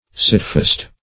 Sitfast \Sit"fast`\, a. [Sit + fast.]